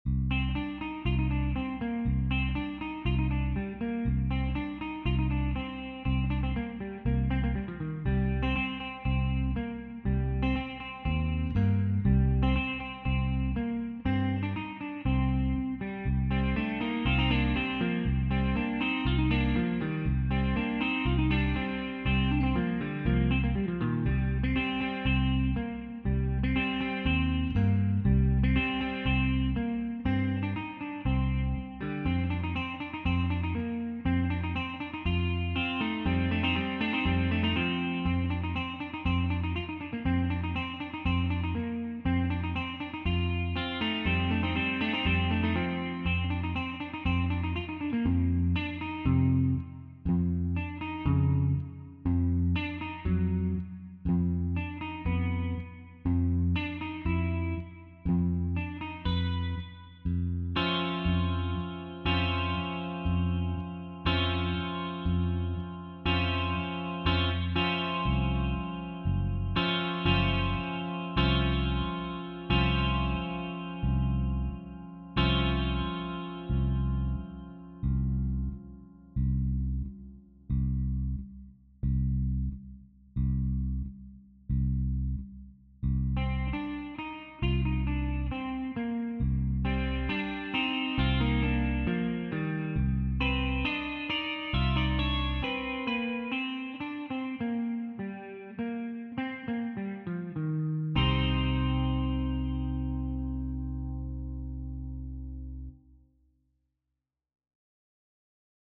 This represents literally decades of guitar foolery.